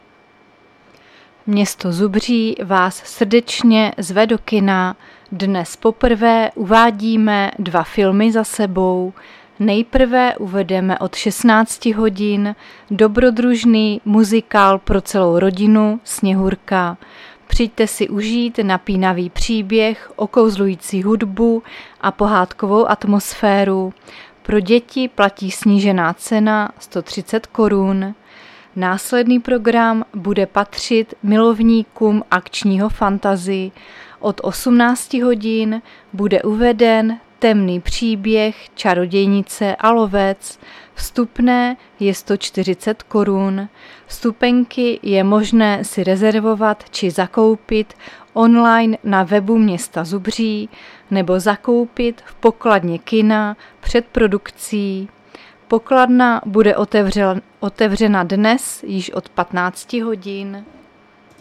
Záznam hlášení místního rozhlasu 26.3.2025
Zařazení: Rozhlas